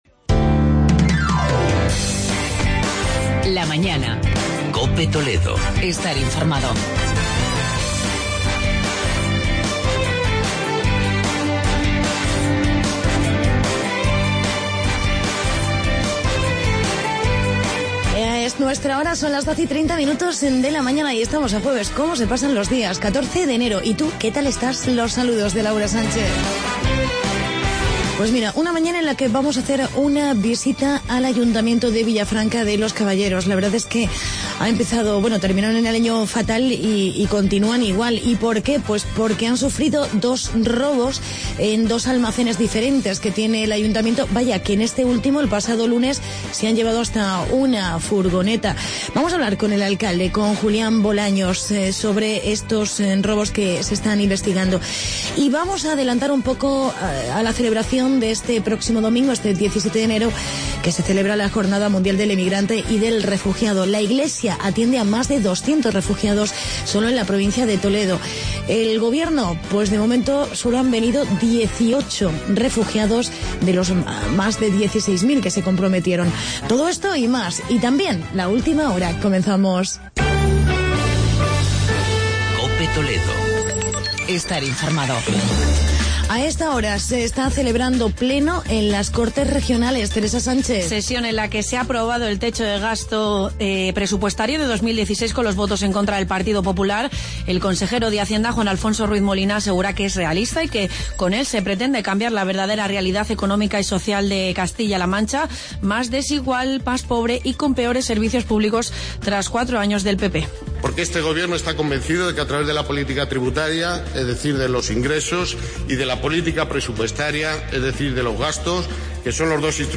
entrevista con el alcalde de Villafranca por los últimos robos.